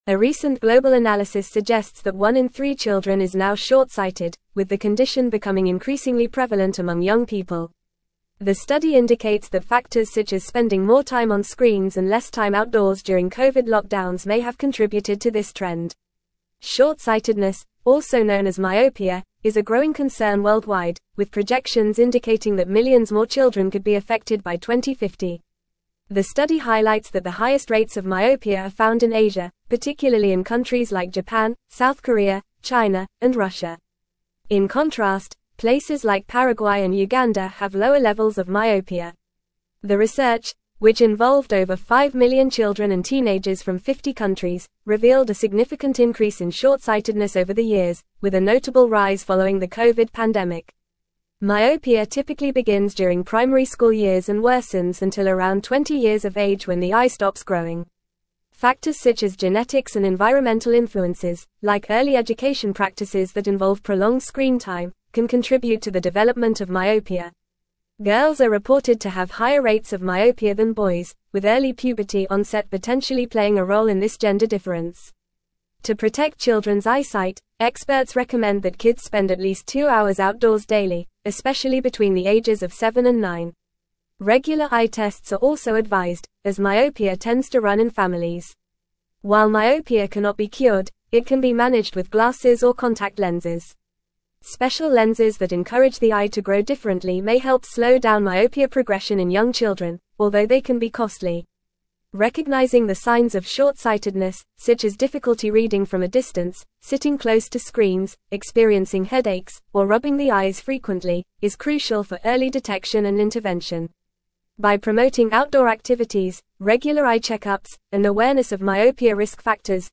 Fast
English-Newsroom-Advanced-FAST-Reading-Rising-Myopia-Rates-in-Children-Global-Concerns-and-Solutions.mp3